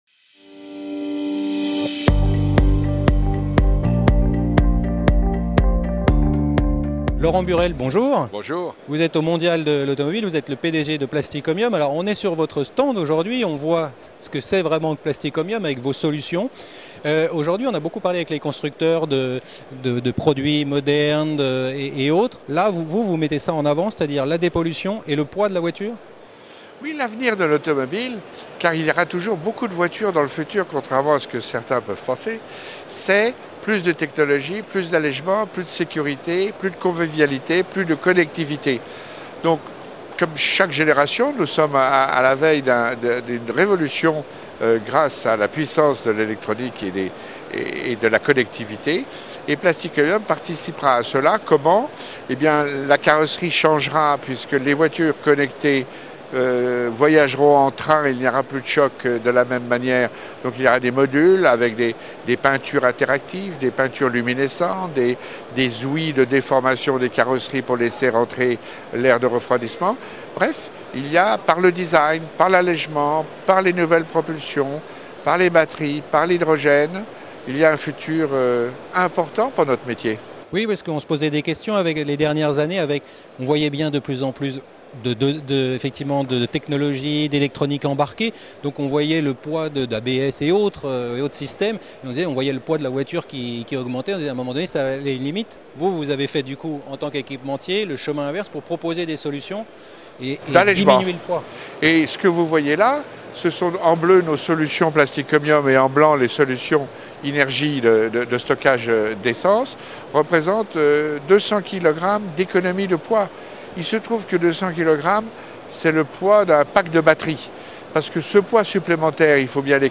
La Web Tv au Mondial de l'Automobile 2016
Sur le Stand de Plastic Omnium, nous voyons les différentes activités de la société et j’ai eu l’occasion d’interroger Laurent Burelle, le Pdg du groupe.
Category: L'INTERVIEW